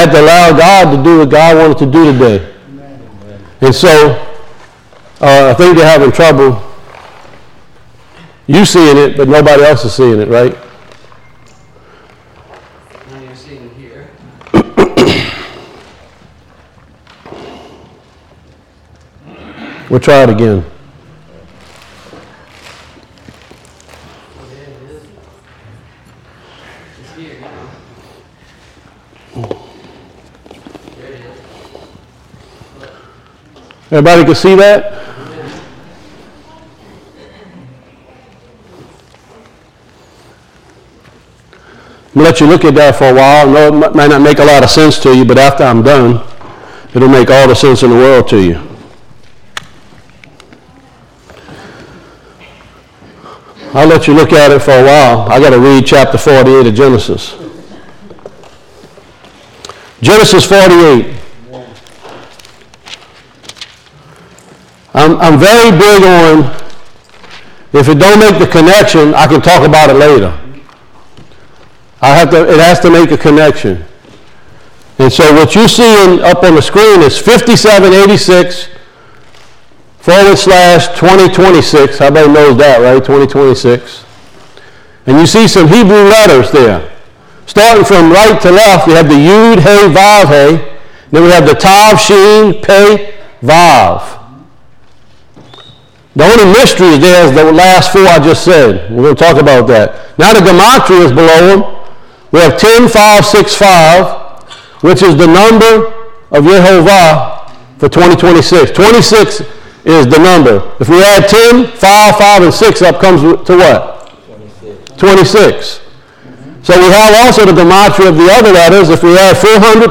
Service Recordings